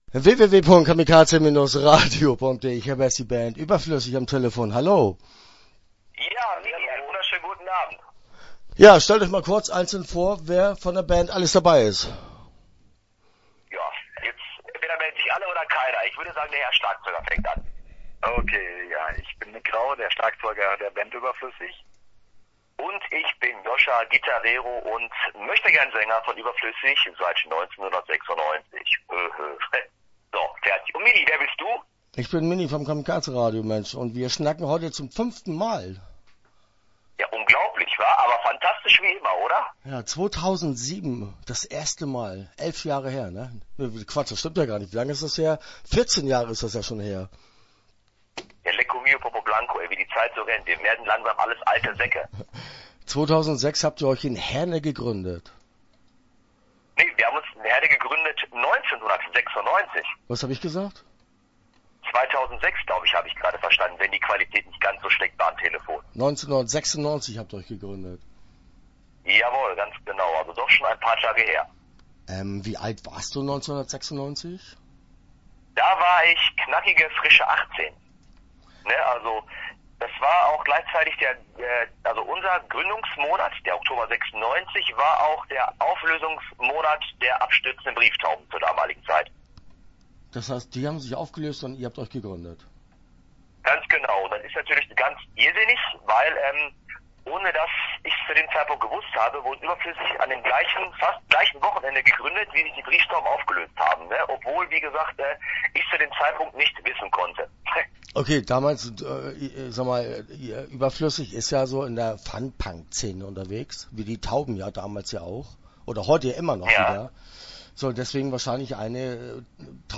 Start » Interviews » Überflüssig